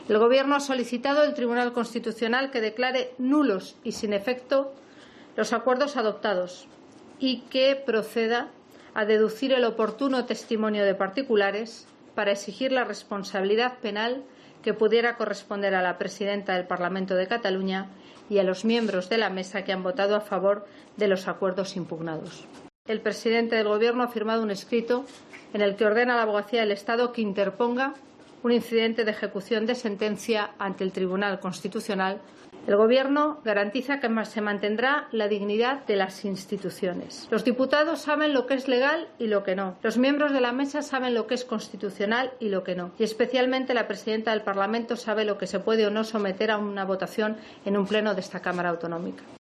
Durante una comparecencia en Moncloa, la vicepresidenta del Gobierno ha denunciado hoy el "abochornante espectáculo" y la "vergüenza" para "cualquier demócrata" que está dando el Parlament de Cataluña con el "falso debate" sobre la ley del referéndum secesionista.